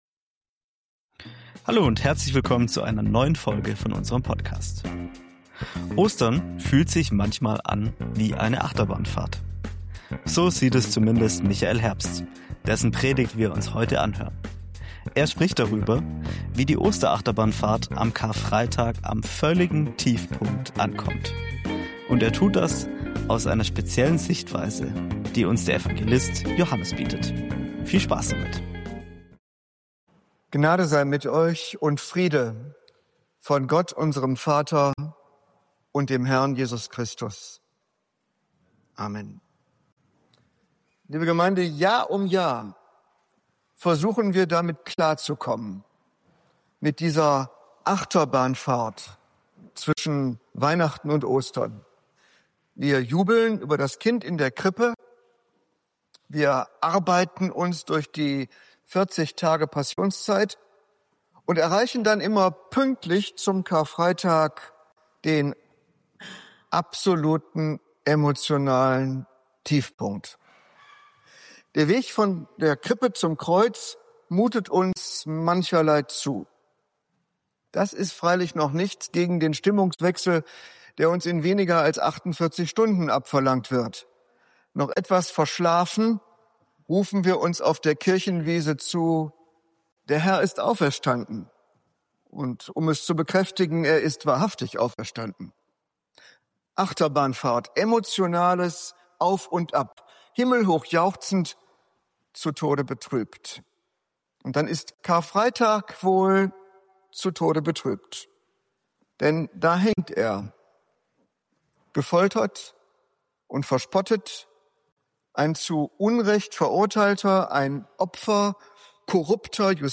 Beschreibung vor 1 Woche In dieser Predigt erkunden wir Johannes' einzigartige Perspektive auf die Passion Jesu und seine Bedeutung als König, der durch Dienst und Opfer regiert. Ein Blick hinter die Kulissen der biblischen Passionsgeschichte offenbart, warum Jesu Kreuz nicht nur Tod, sondern auch Herrschaft durch Liebe bedeutet.